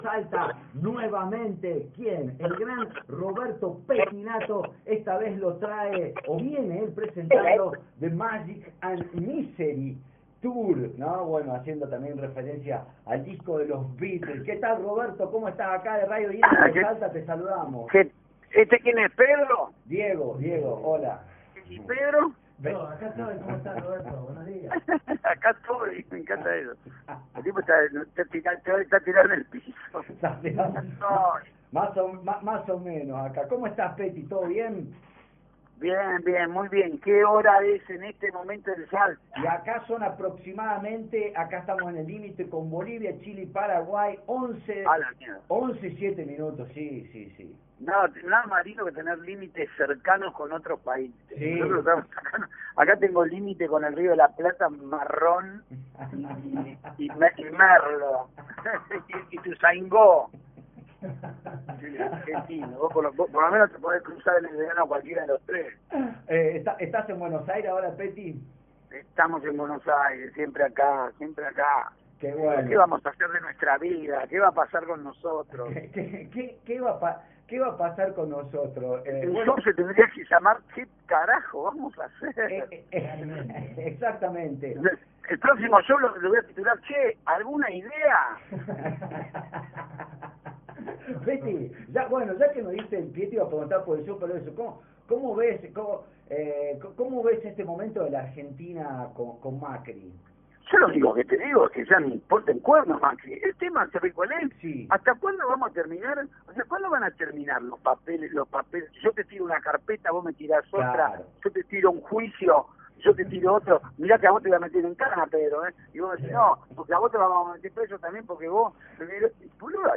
roberto-pettinato-nota.mp3